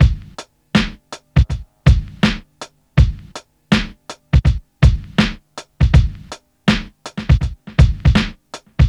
• 108 Bpm '90s Breakbeat Sample C# Key.wav
Free breakbeat - kick tuned to the C# note. Loudest frequency: 502Hz
108-bpm-90s-breakbeat-sample-c-sharp-key-mIL.wav